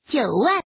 Index of /client/common_mahjong_tianjin/mahjonghntj/update/1307/res/sfx/woman/